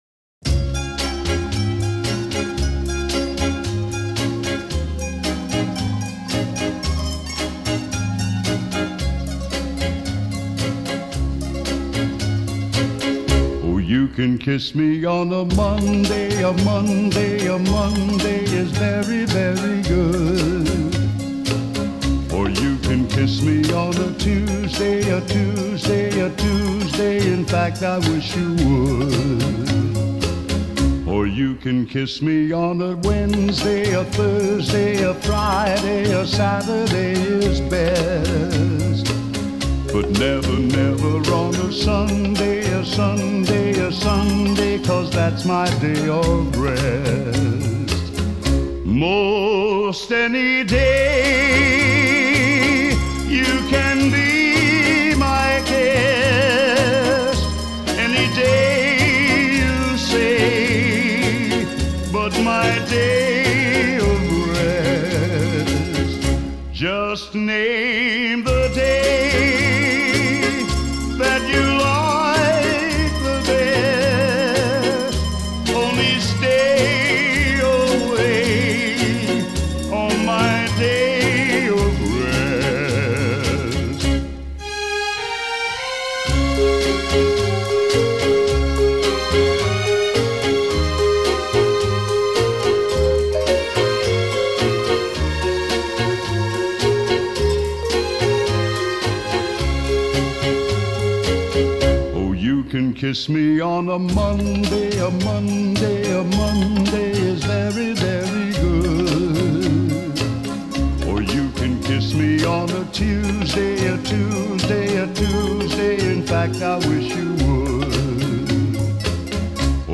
风格流派：Classical Pop & Swing
piano